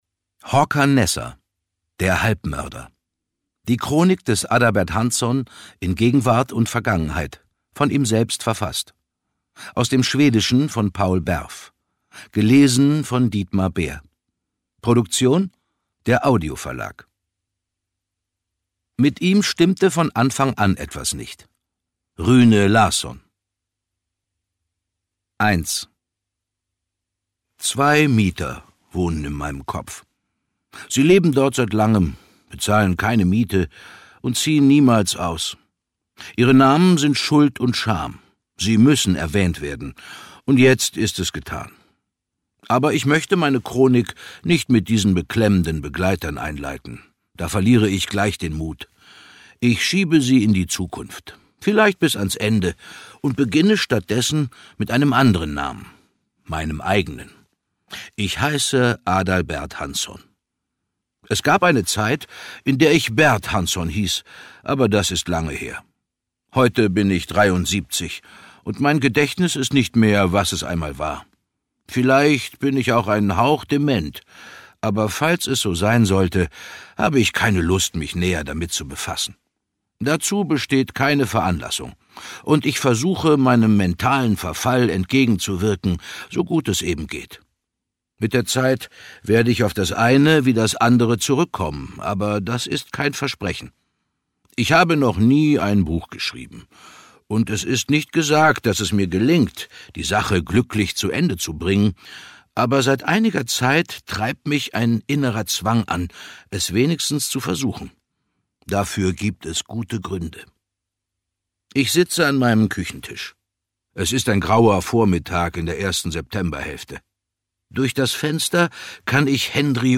Dietmar Bär (Sprecher)
Ungekürzte Lesung mit Dietmar Bär